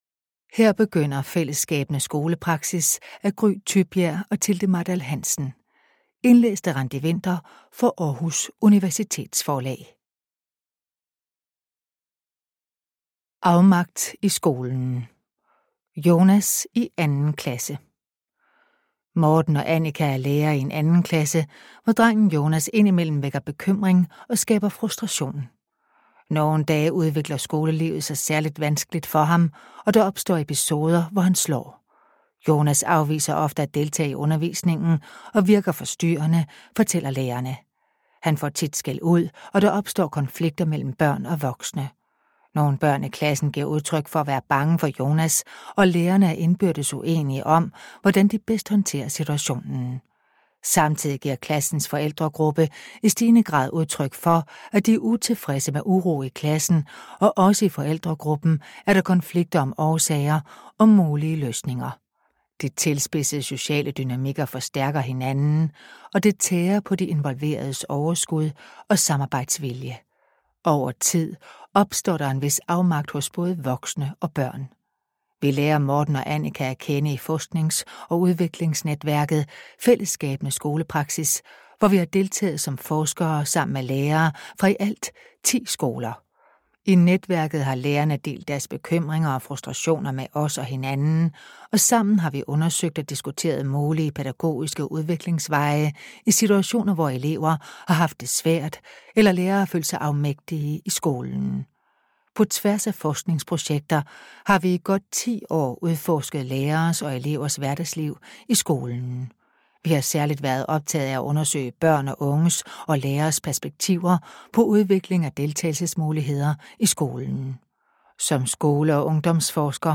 Fællesskabende+skolepraksis+LYDBOG.mp3